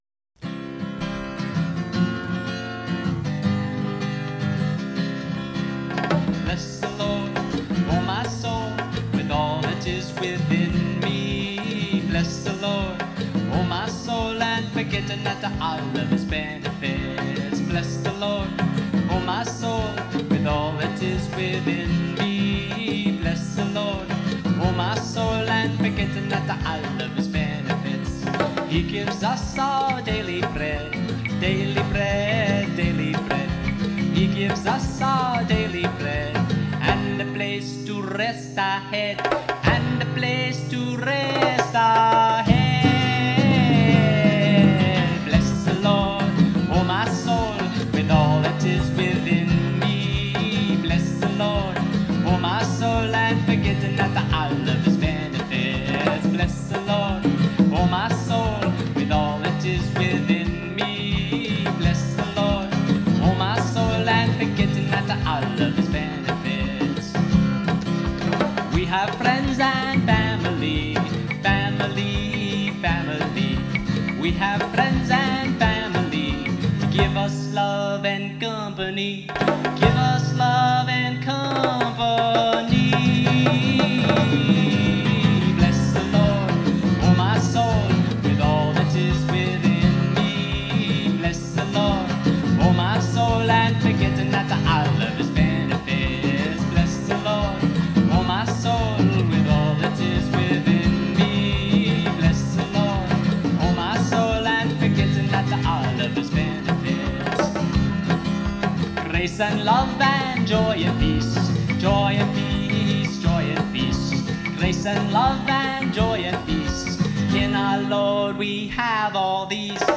a spirited, joyous song of thanksgiving